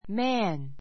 mǽn マ ン